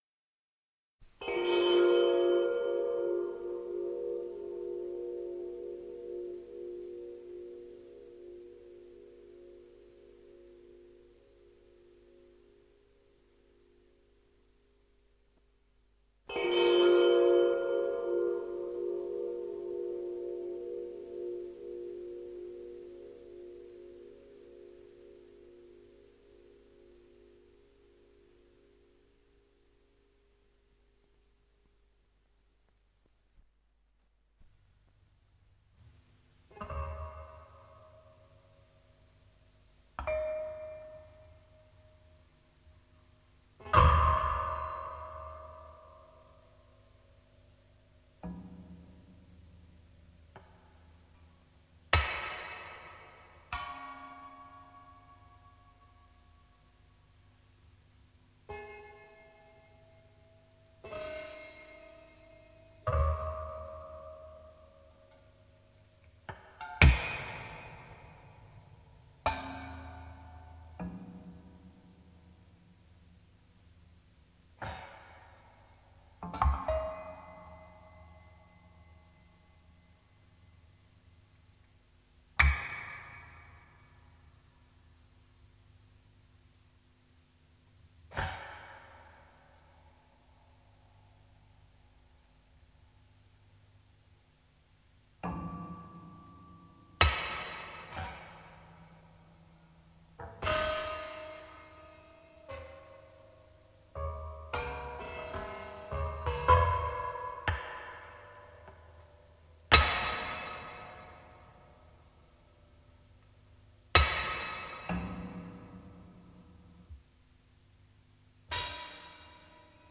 Soundtrack score
Japanese Avant-Garde composer
beguiling and utterly creepy score